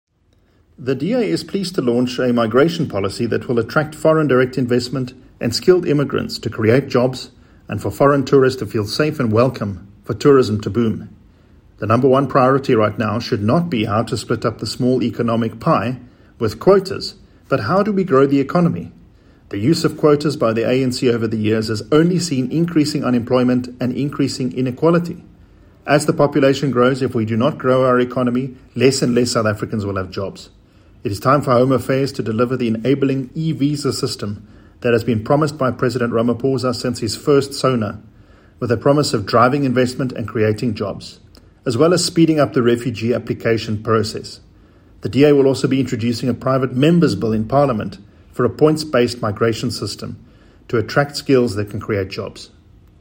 Please find attached soundbites by Gwen Ngwenya DA Head of Policy;